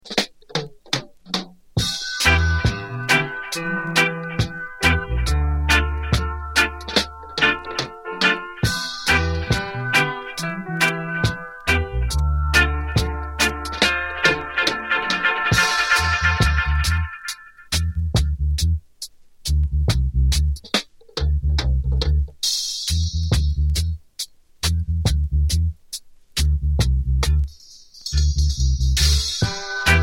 Reggae Ska Dancehall ...